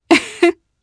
Demia-Vox_Happy1_jp.wav